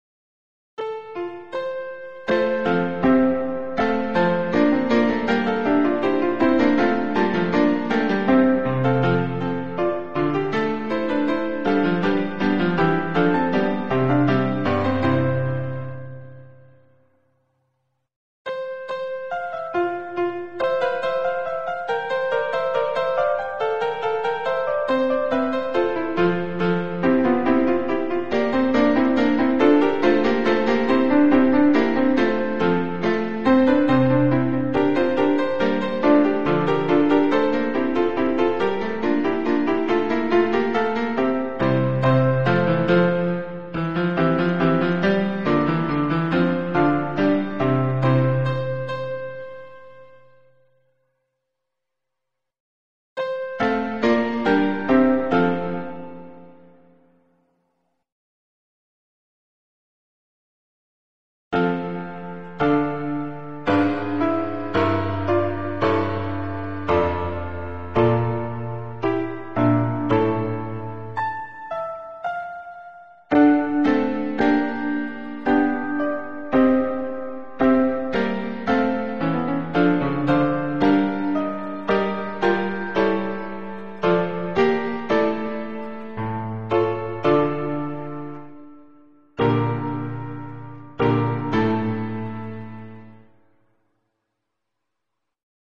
MIDI
Sans paroles
Voix + chœur en sourdine